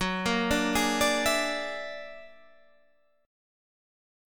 F#+9 Chord
Listen to F#+9 strummed